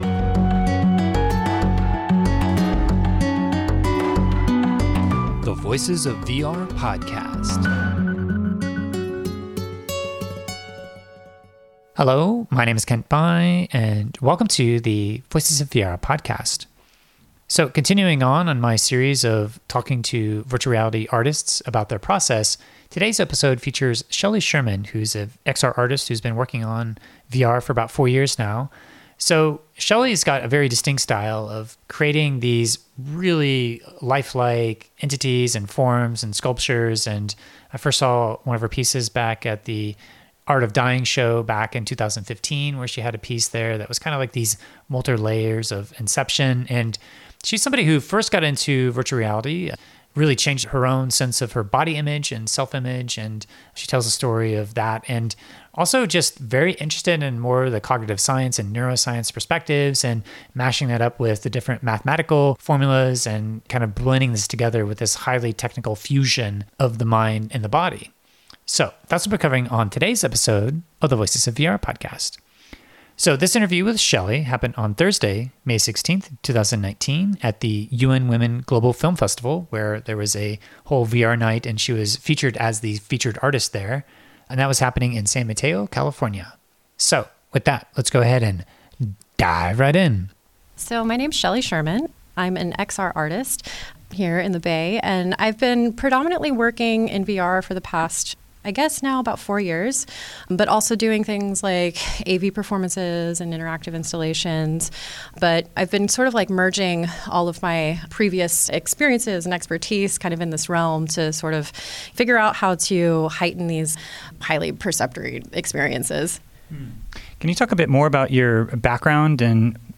in San Mateo, CA in May 2019